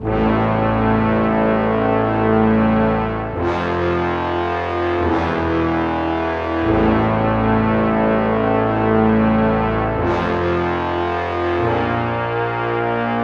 描述：用DSK BRASS制作的铜环，使用短号铜管乐器。
Tag: 145 bpm Hip Hop Loops Brass Loops 2.23 MB wav Key : Unknown